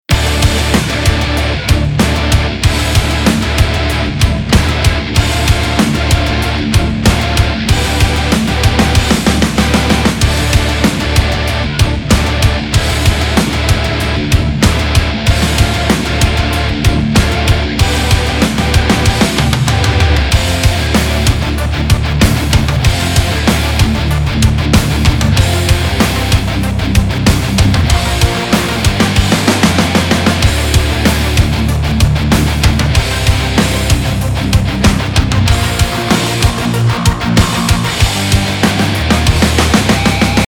Помогите накрутить модерн-метал звук